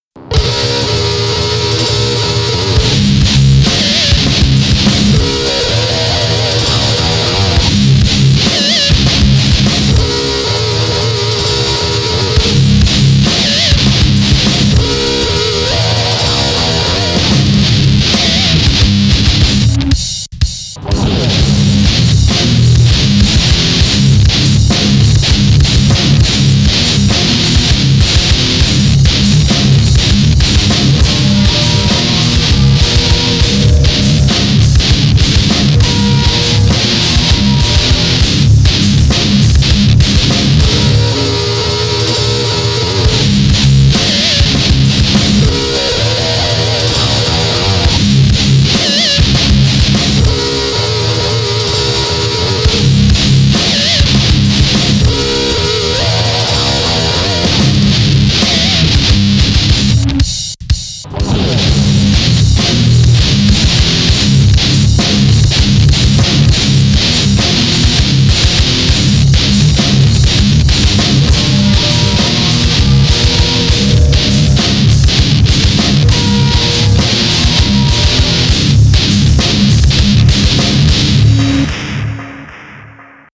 mini instrumental 2007